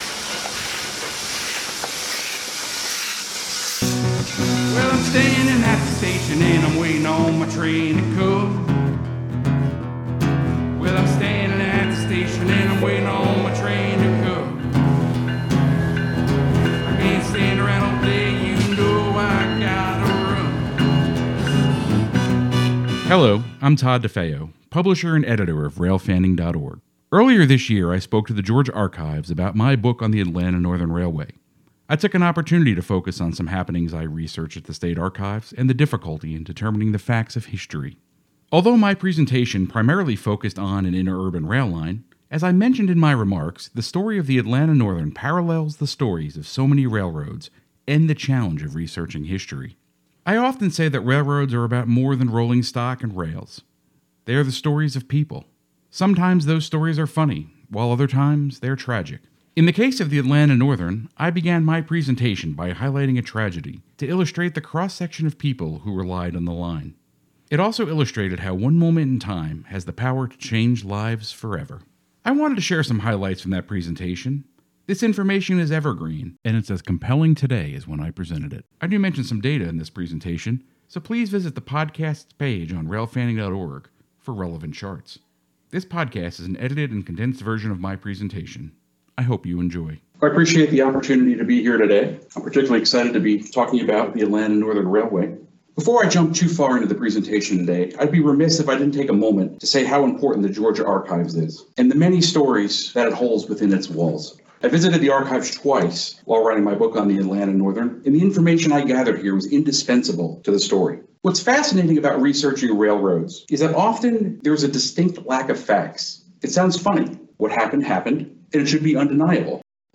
This podcast is an edited and condensed version of my presentation.